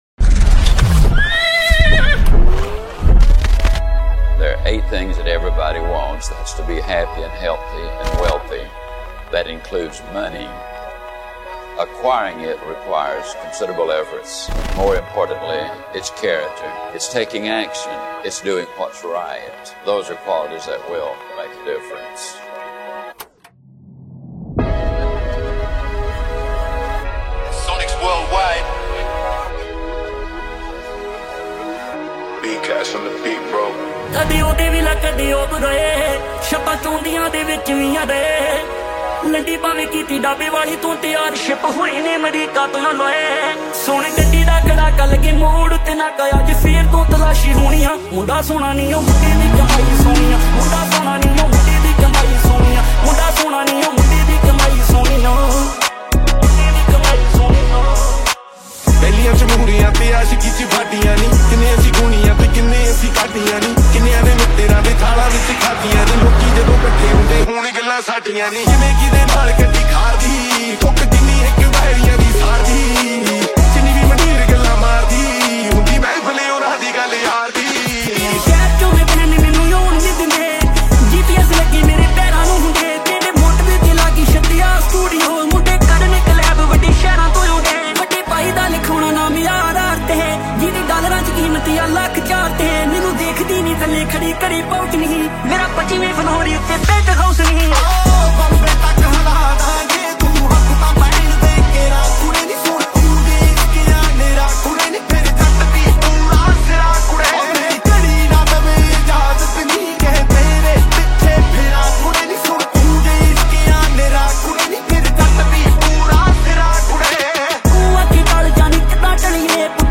Releted Files Of DJ Remix Punjabi